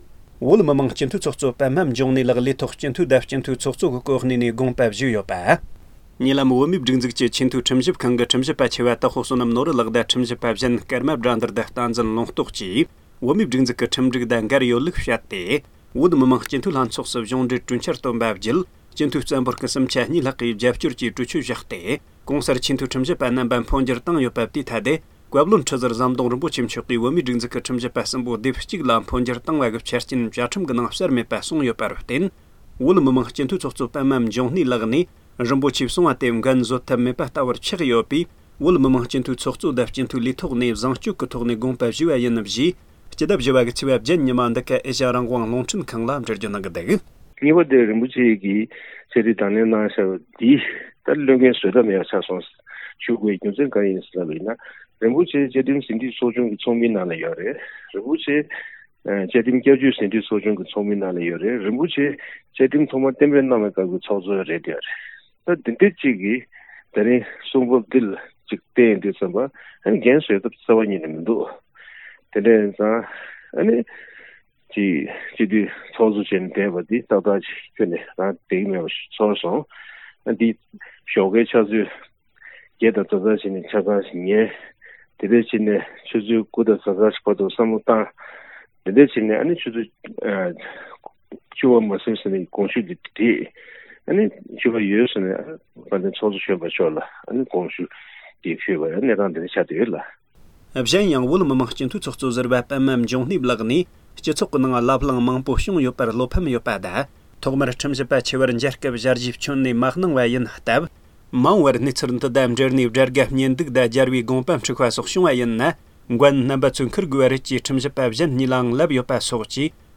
བོད་མི་མང་སྤྱི་འཐུས་ལྷན་ཚོགས་ཀྱི་ཚོགས་མི་པདྨ་འབྱུང་གནས་ལགས་ཀྱིས་ཁོང་སྤྱི་འཐུས་ཀྱི་ཚོགས་གཙོའི་ལས་འགན་དང་སྤྱི་འཐུས་སུ་མུ་མཐུད་དུ་གནས་ཐབས་བྲལ་བ་གསུངས་ཏེ་རྩ་དགོངས་ཞུས་པ་ཡིན་པ་ཨེ་ཤེ་ཡ་རང་དབང་རླུང་འཕྲིན་ཁང་ལ་ཞལ་པར་ཐོག་གསུངས་བྱུང་།